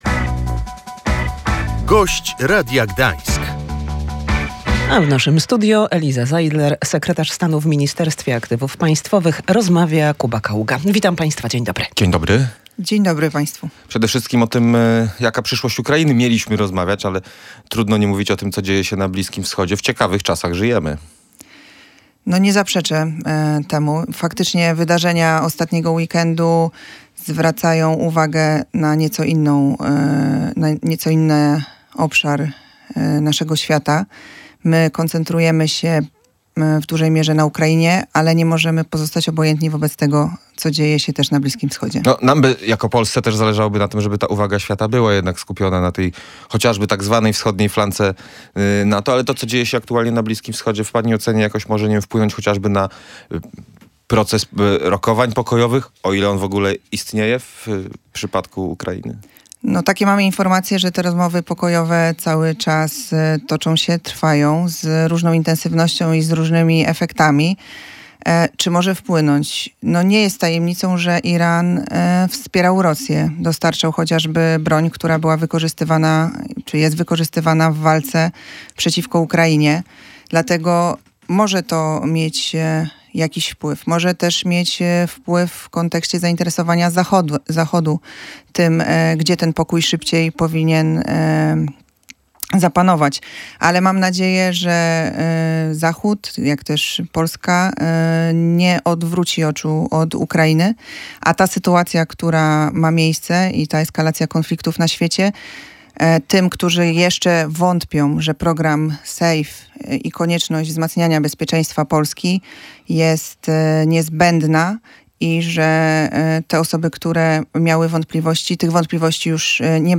Duże doświadczenie Gdańska zdecydowało o tym, że to właśnie w tym mieście w czerwcu zorganizowana zostanie międzynarodowa konferencja dotycząca odbudowy Ukrainy – mówiła w Radiu Gdańsk Eliza Zeidler, sekretarz stanu w Ministerstwie Aktywów Państwowych.